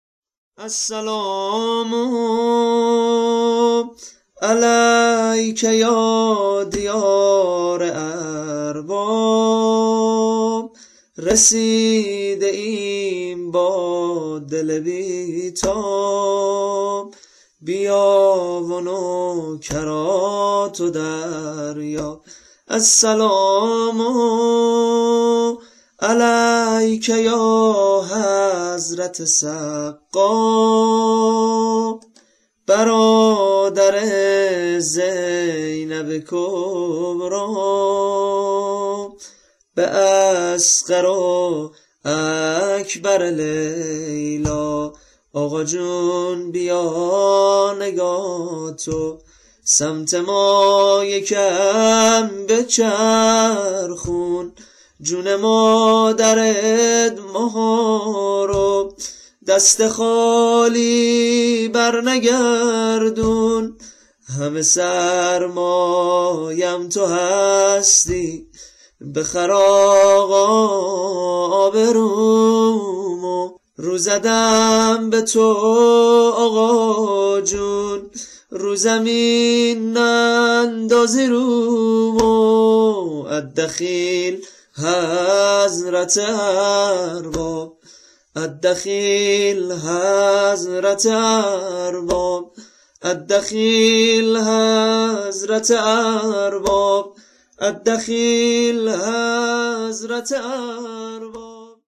متن نوحه واحد برای زائرین کربلای حسینی -( السلام علیک یا دیار ارباب )